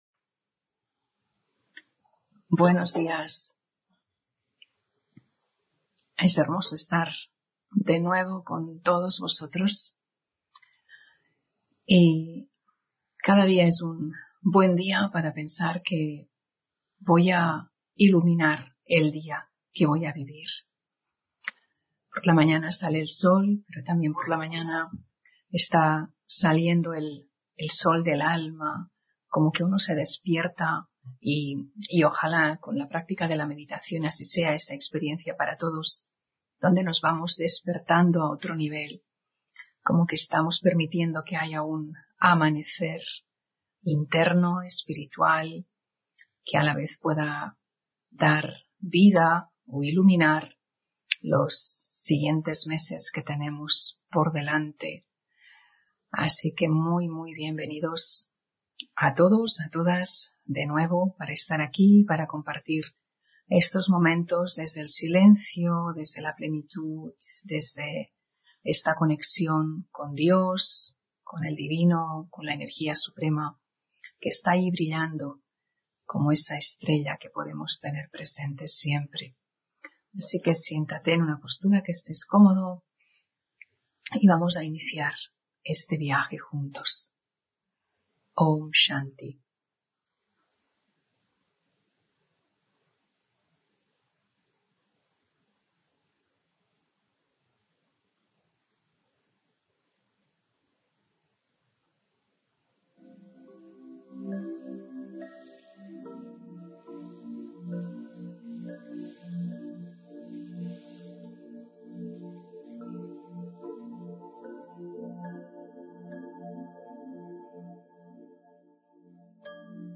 Meditación de la mañana: Un corazón de oro es grande